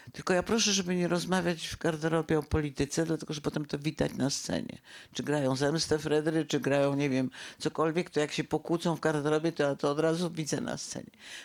Krystyna Janda spotkała się z fanami w sali kinowej Narodowego Centrum Kultury Filmowej.
Sala kinowa była pełna, a Janda opowiadała nie tylko o produkcji, którą wcześniej mieli okazję obejrzeć łodzianie, ale także o teatrze, początkach swojej kariery, czy prowadzonej przez siebie fundacji.